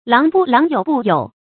稂不稂莠不莠 注音： ㄌㄤˊ ㄅㄨˋ ㄌㄤˊ ㄧㄡˇ ㄅㄨˋ ㄧㄡˇ 讀音讀法： 意思解釋： 既不象稂，也不象莠。